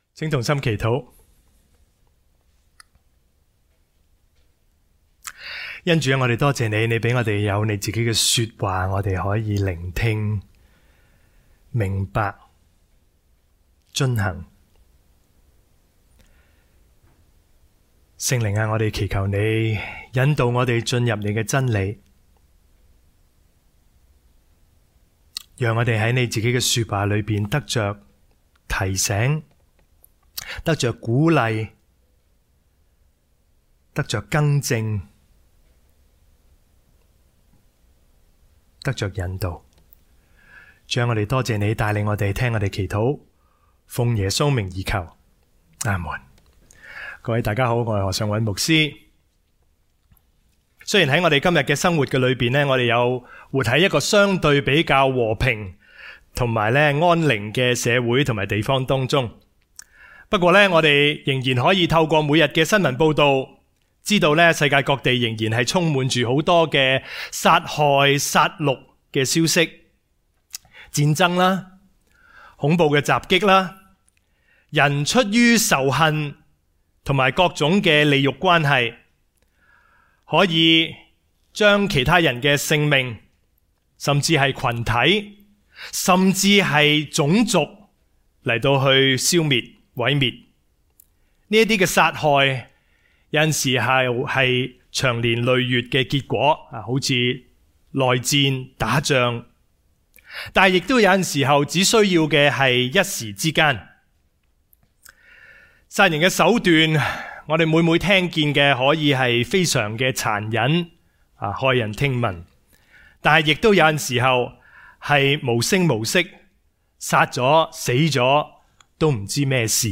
講道錄音：